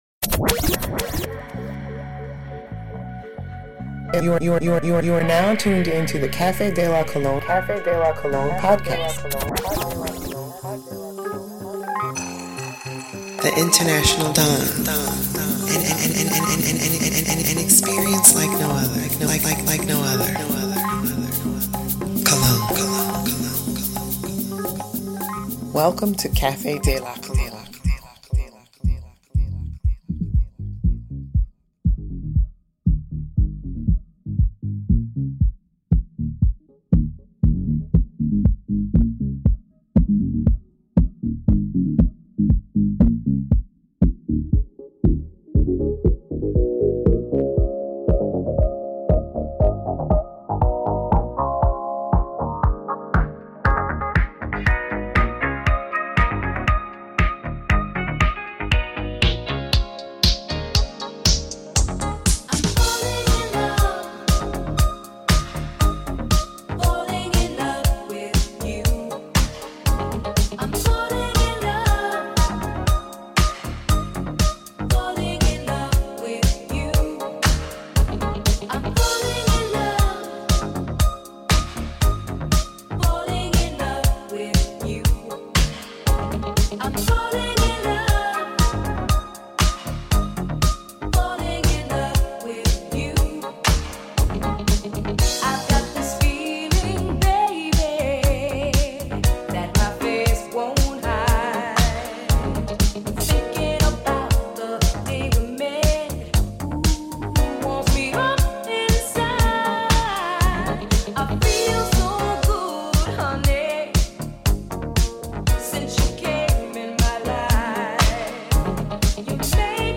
designer music